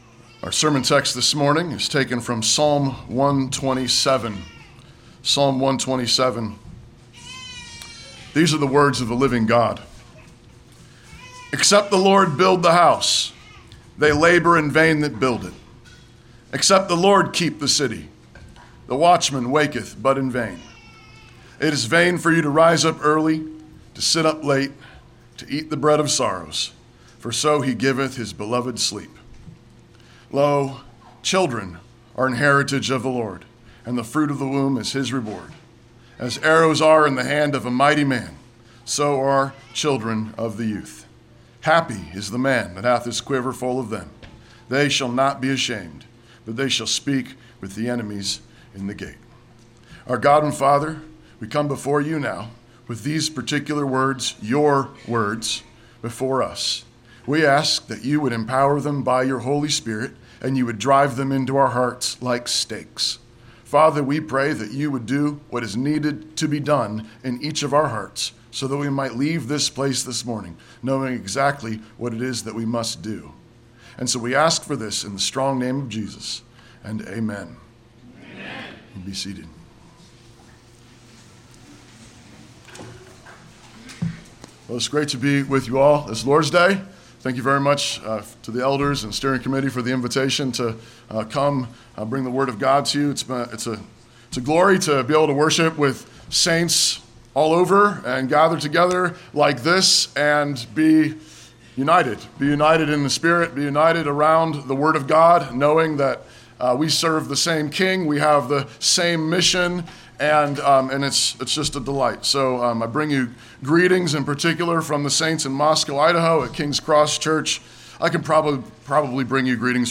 An Election, All-Saints, Child-Rearing Sermon
Passage: Psalm 127 Service Type: Sunday Sermon Download Files Bulletin « What is the Church?